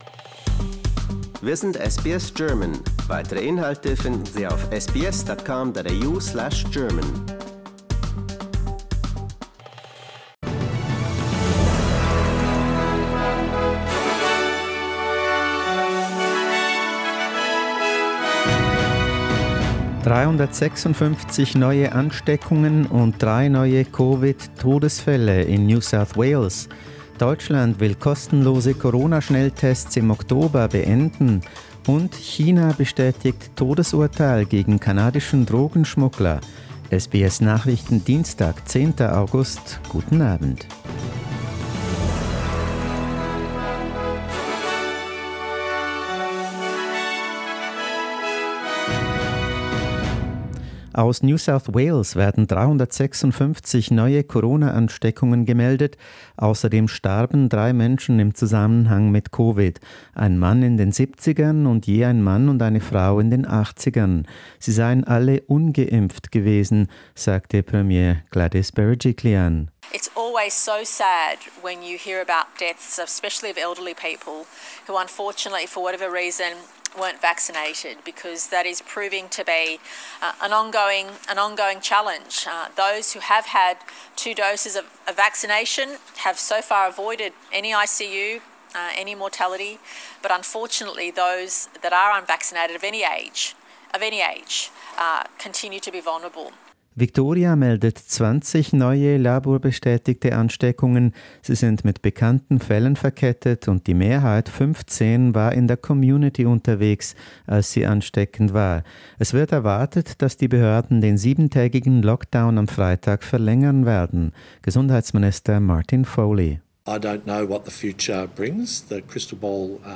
SBS Nachrichten, Dienstag 10.08.21